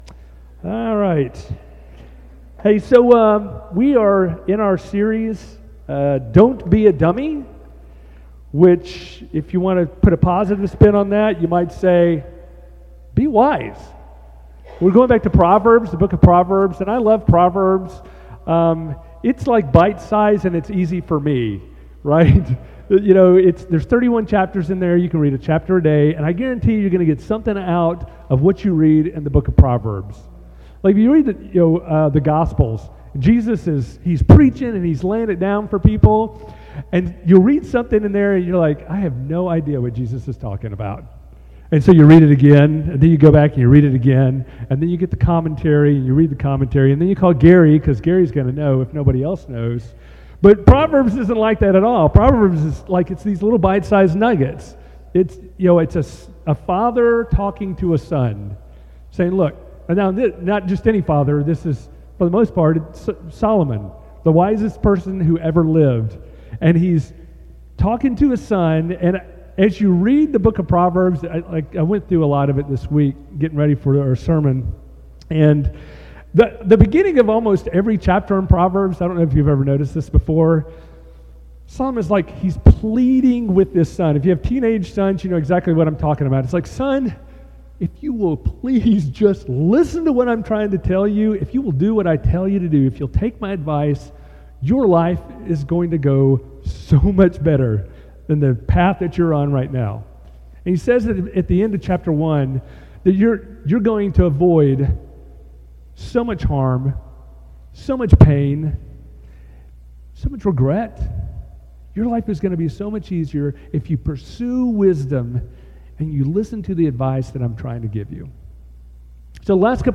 Most Recent Sermons Older Sermons Audio Sermons